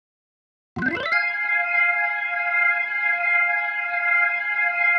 Oakland Organ.wav